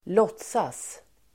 Uttal: [²l'åt:sas (el. ²l'ås:as)]